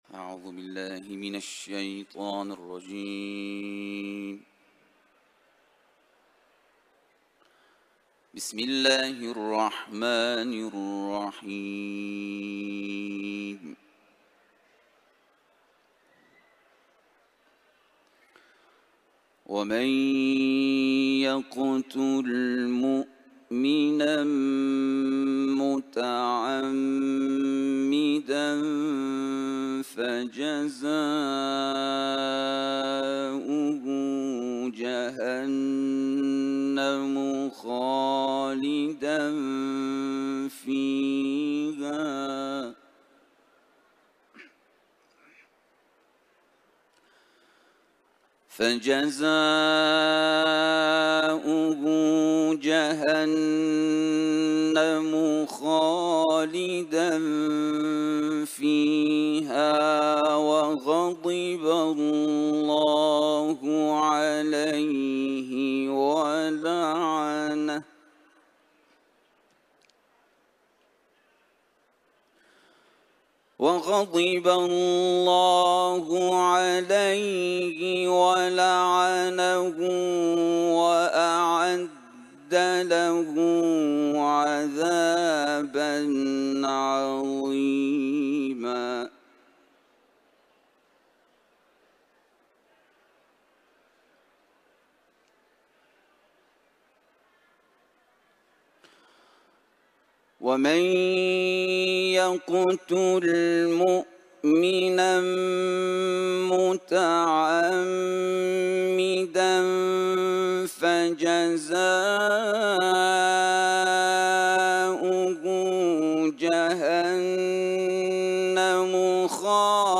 Kur’an tilaveti